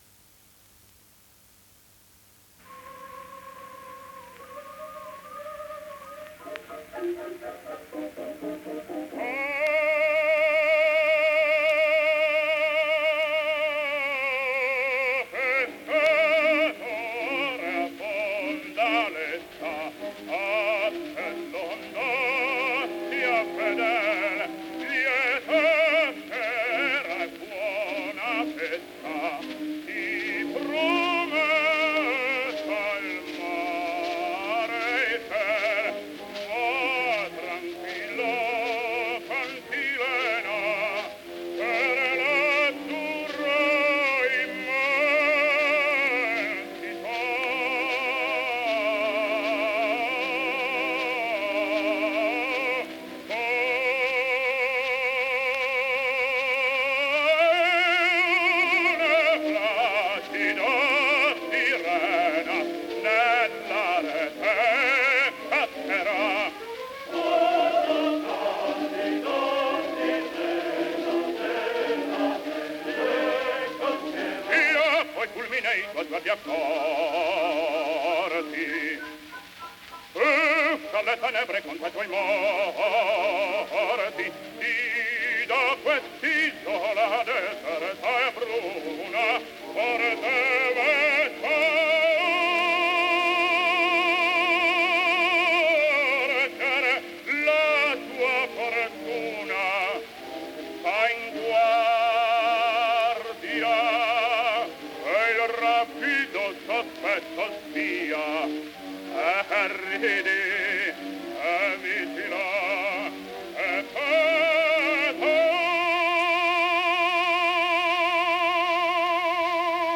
Sotto ascolterete tre brani da incisioni acustiche, e una registrazione dove scherza con la voce di Chaliapin.